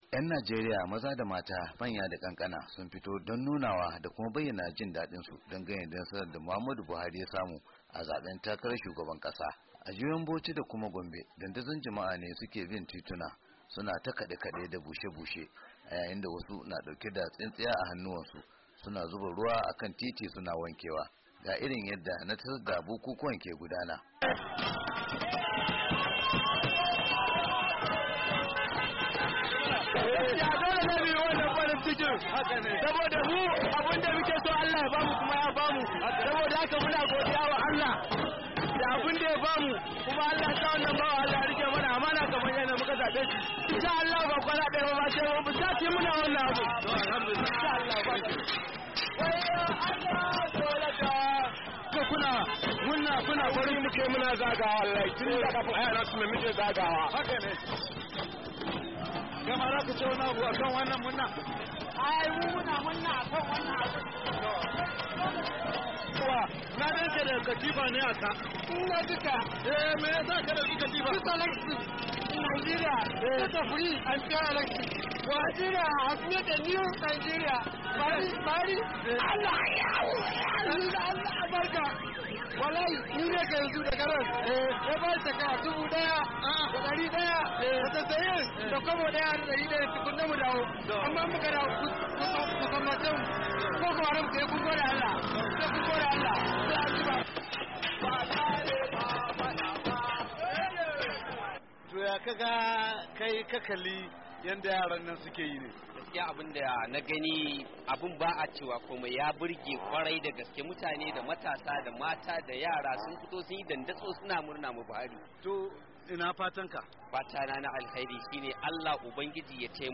Wani daga cikin mutanen dake kade-kade da raye-rayen ya bayyana dalilinsa da kuma fatan su ga wannan sabuwar gwamnatin,